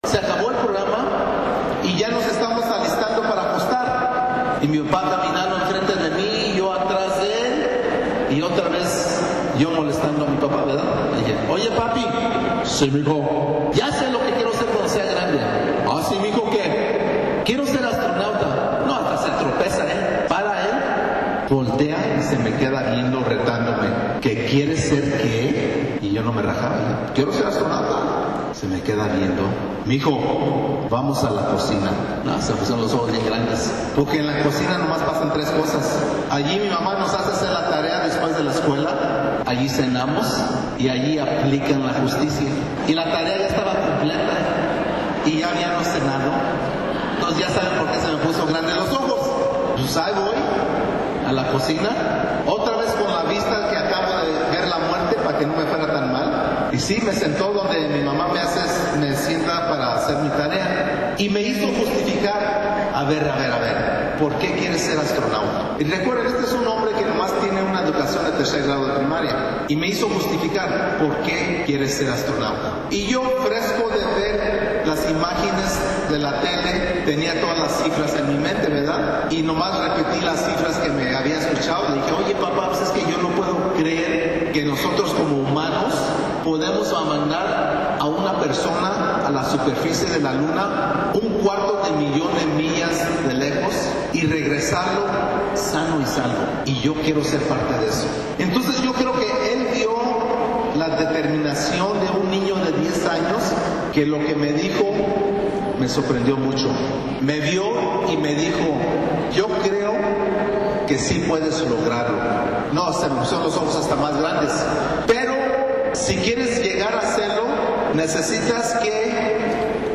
ADEMAS COMPARTIO CON LOS JOVENES UNA RECETA, UNA SERIE DE PASOS PARA LOGRAR EL EXITO EN LA VIDA.